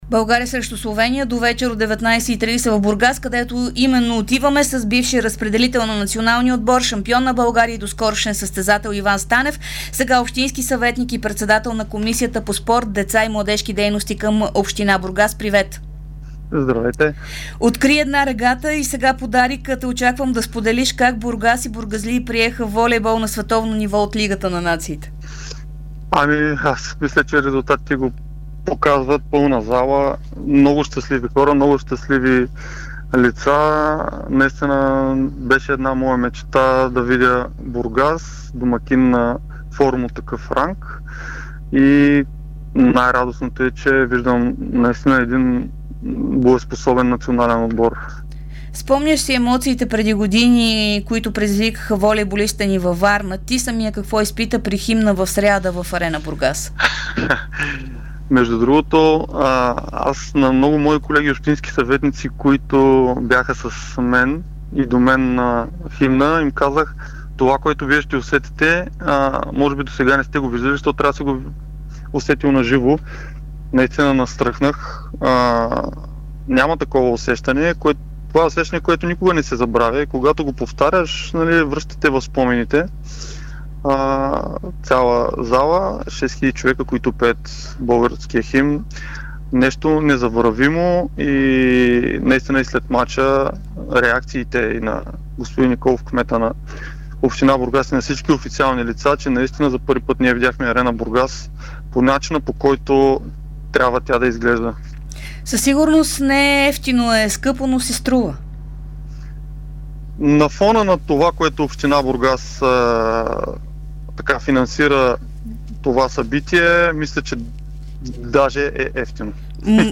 Иван Станев, доскорошен активен волейболист, бивш национал и настоящ общински съветник в Бургас, говори специално за Дарик радио преди двубоя на България срещу Словения. Станев коментира новоизградения и много боеспособен състав на „лъвовете“, какво е чувството да чуеш българския химн пред пълната „Арена Бургас“ и 350-те хиляди лева, които Общината на морския град е отпуснала за организацията на домакинствата.